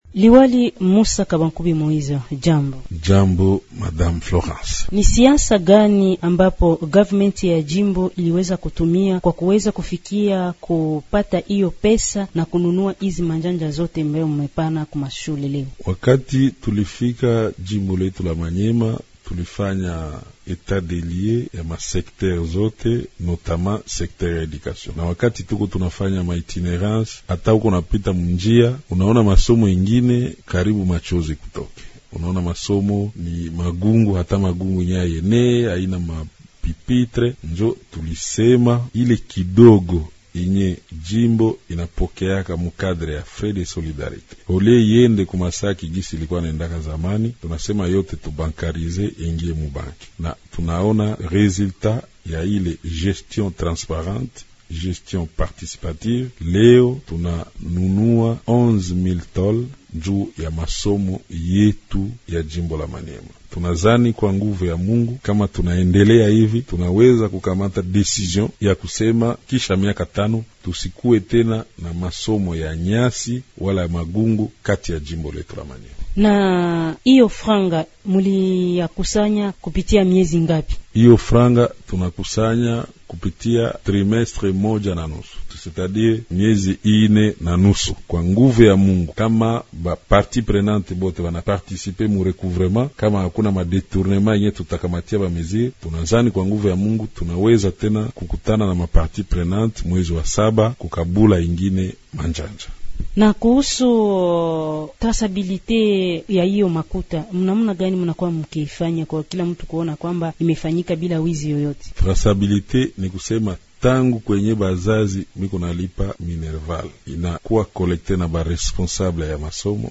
Tunamkaribisha mkuu wa mkoa wa Maniema. MUSSA KABWANKUBI MOÏSE anapigana vita dhidi ya shule zinazojengwa kwa majani na vibanda katika jimbo lake. Amekabidhi mabati 11,000 kwa shule katika tarafa 19 katika mikoa miwili ya elimu ya Maniema kama sehemu ya ada ya mshikamano wa shule.